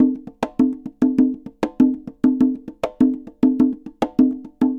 Congas_Samba 100_2.wav